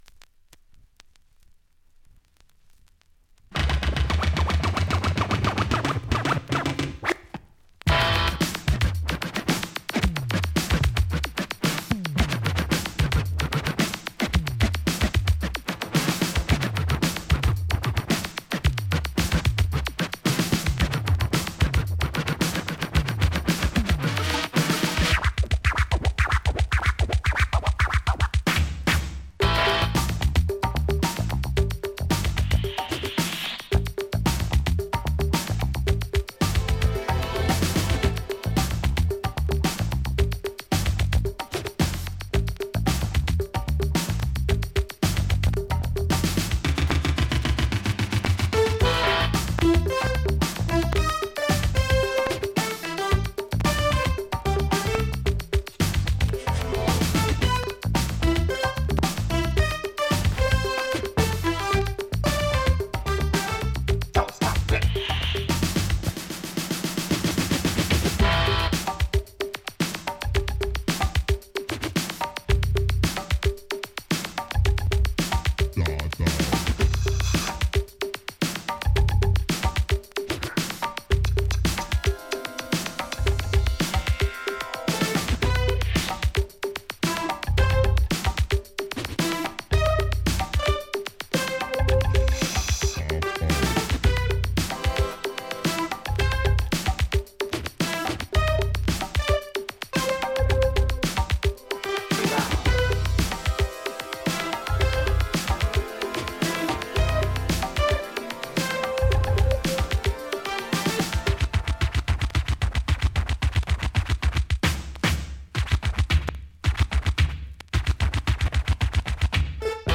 USA盤 オリジナル 7"Single 45 RPM現物の試聴（両面すべて録音時間９分３２秒）できます。